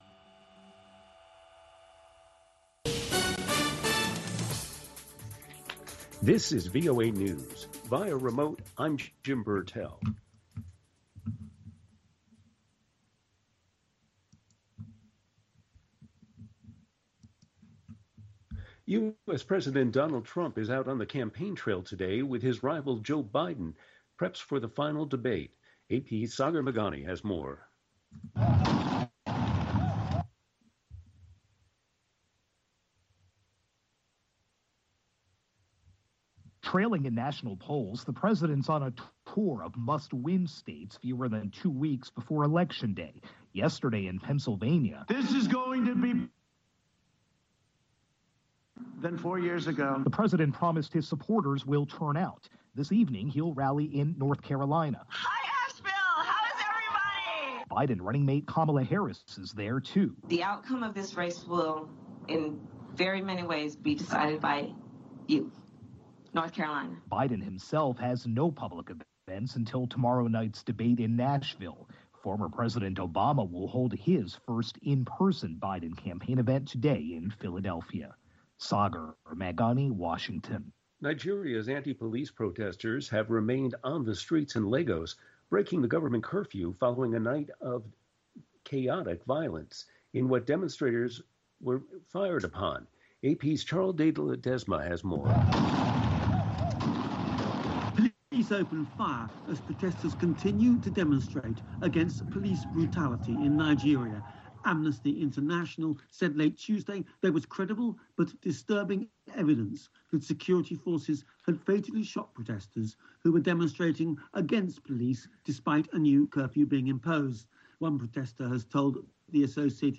Two Minute Newscast